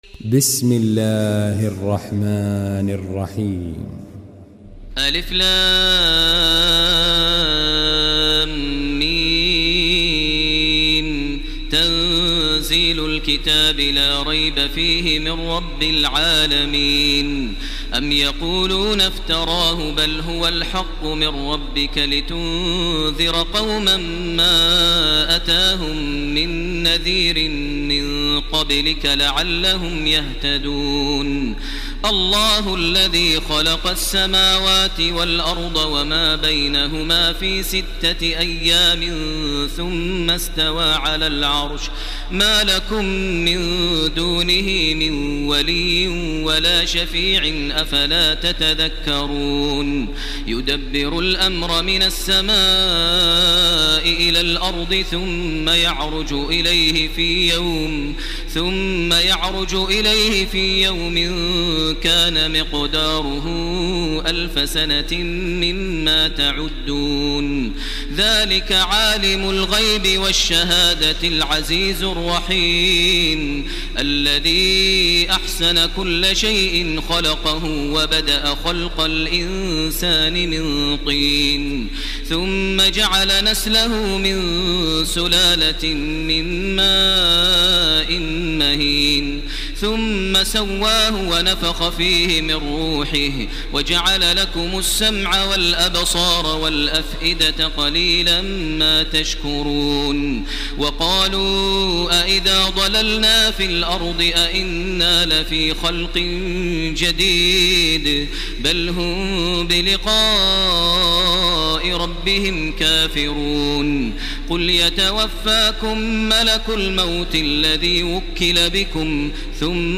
تراويح ليلة 21 رمضان 1430هـ سورتي السجدة و الأحزاب Taraweeh 21 st night Ramadan 1430H from Surah As-Sajda and Al-Ahzaab > تراويح الحرم المكي عام 1430 🕋 > التراويح - تلاوات الحرمين